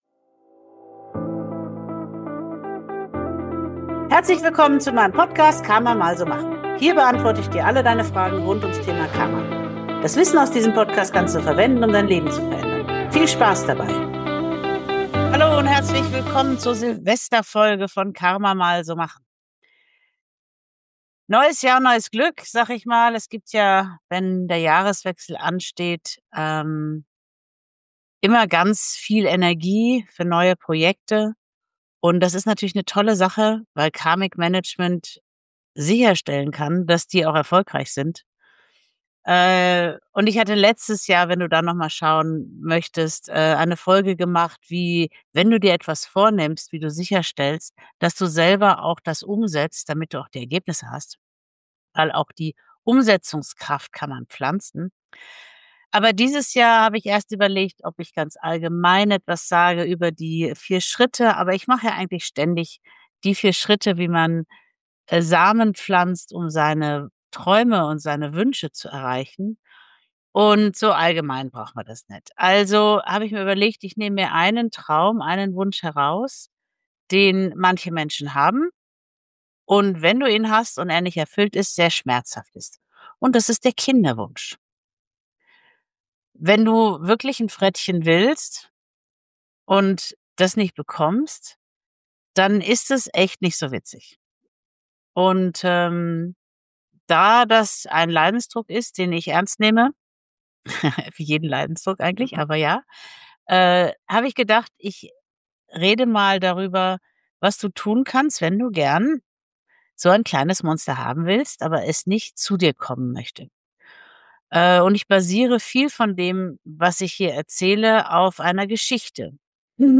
Ich habe Dir eine geführte Dankbarkeits-Meditation aufgenommen, die sich sowohl für Einsteiger als auch für Erfahrene eignet. Nutze diese Folge als kurze Praxis um Wunder zu Weihnachten zu erschaffen.